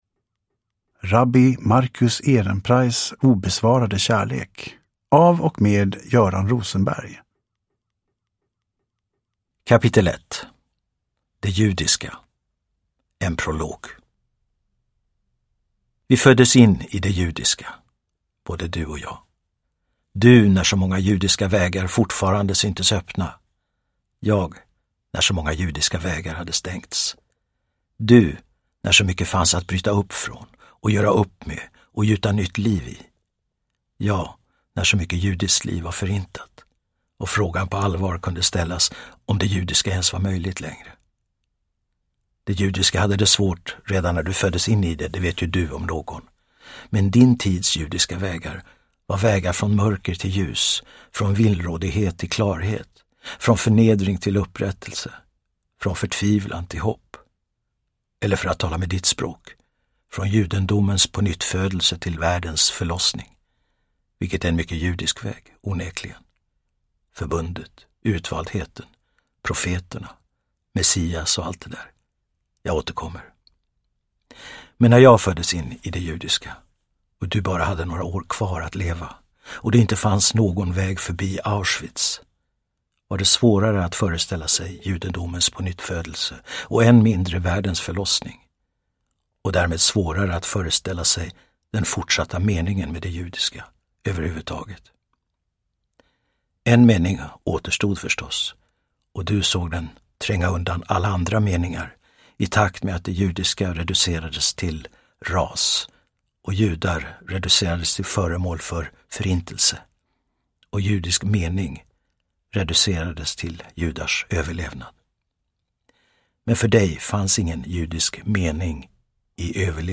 Uppläsare: Göran Rosenberg
Ljudbok